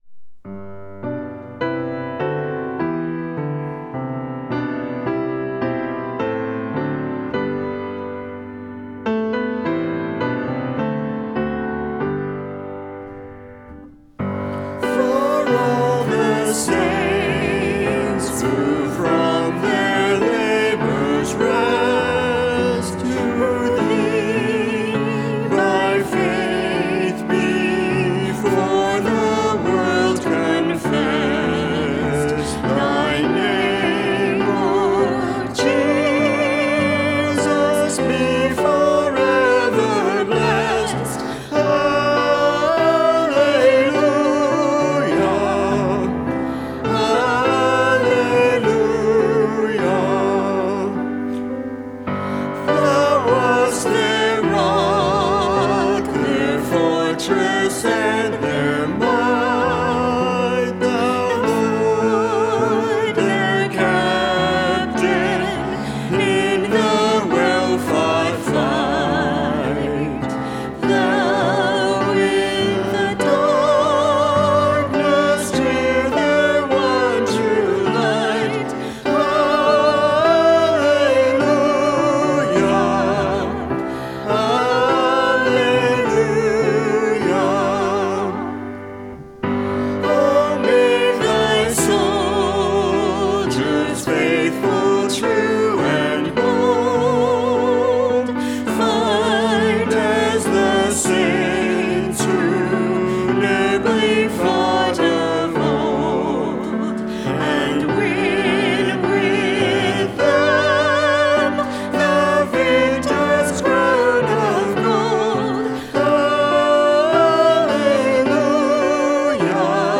Service of Worship
Closing Hymn — #711 For All the Saints (v. 1-4)